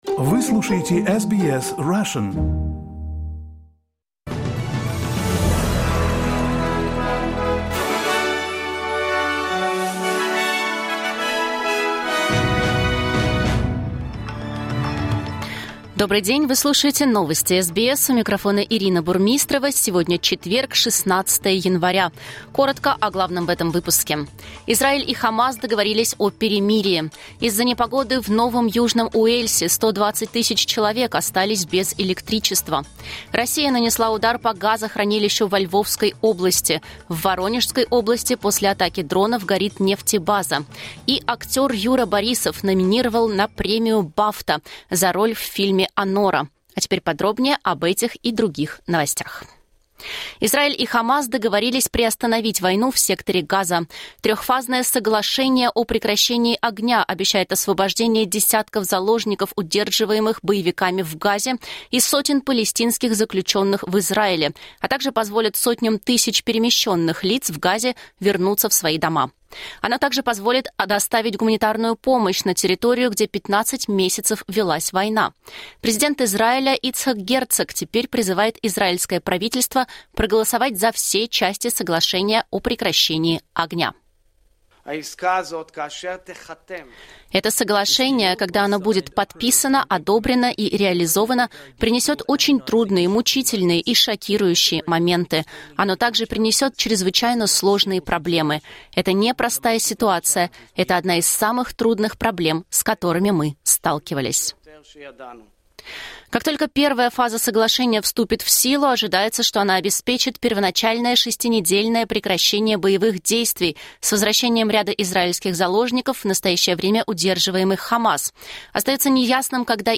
Новости SBS на русском языке — 16.01.2025